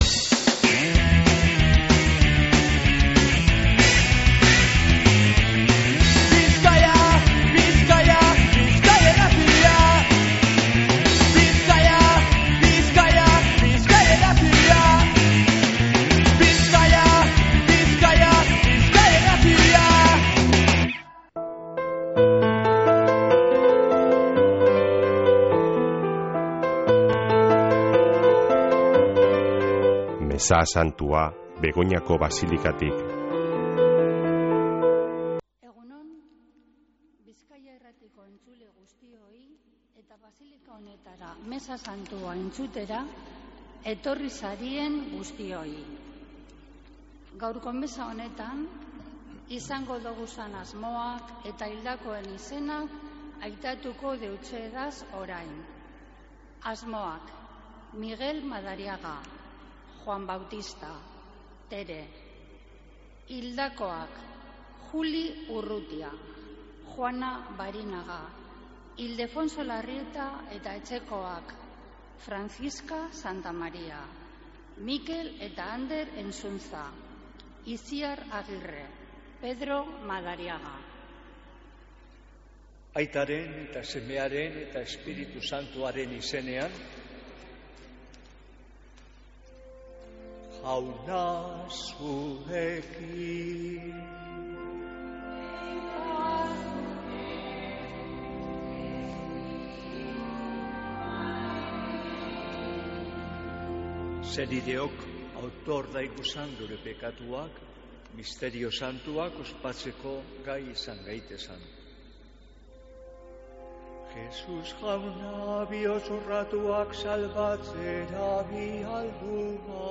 Mezea Begoñako basilikatik | Bizkaia Irratia
Mezea (25-06-30)